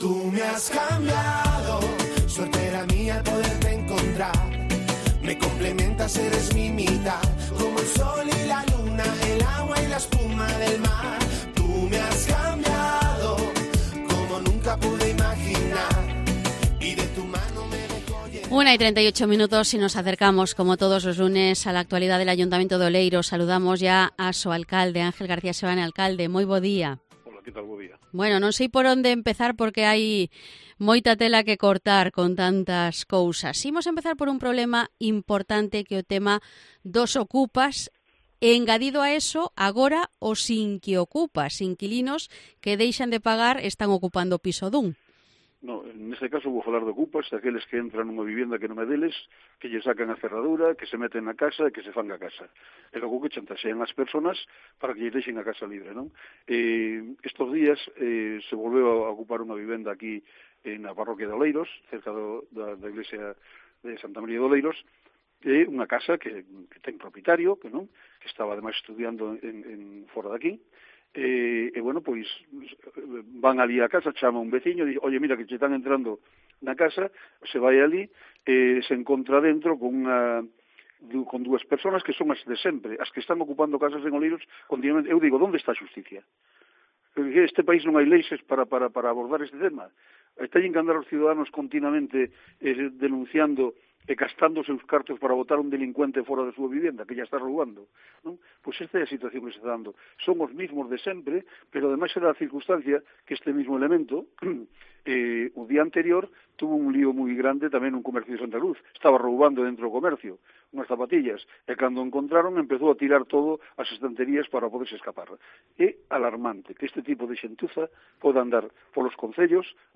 Entrevista al alcalde de Oleiros, Ángel García Seoane